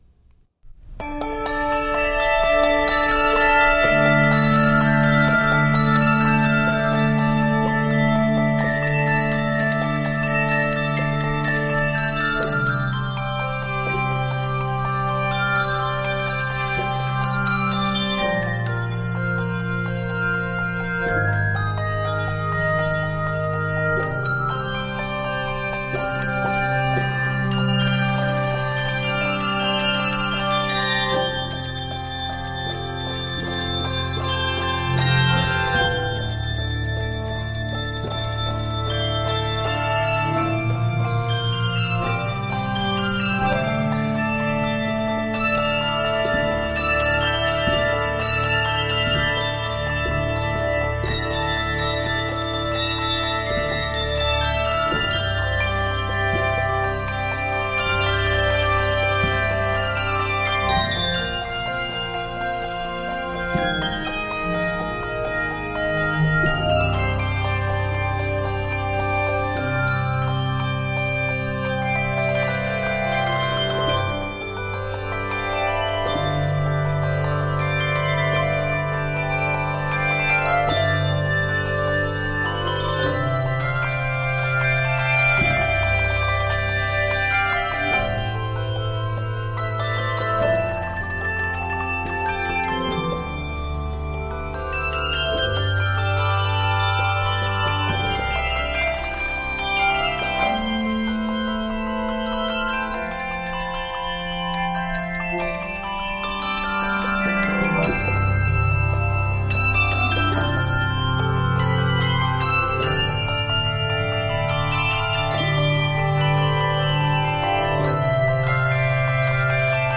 N/A Octaves: 5-7 Level